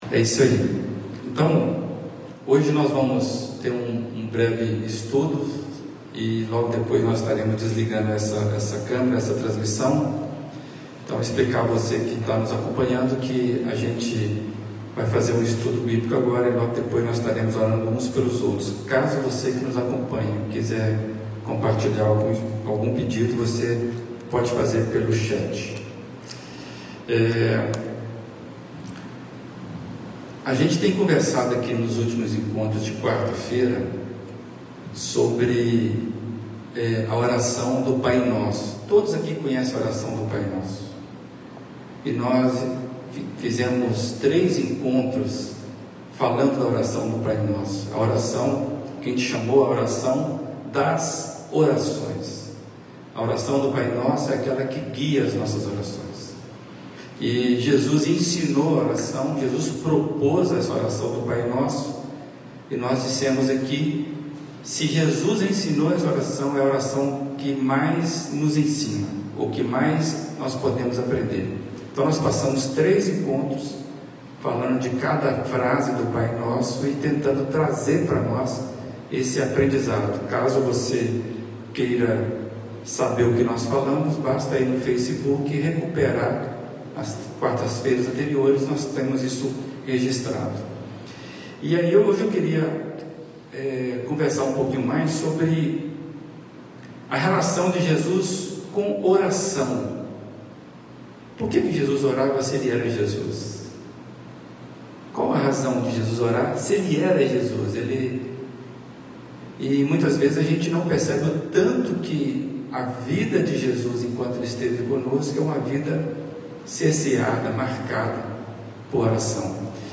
Primeira Igreja Batista de Brusque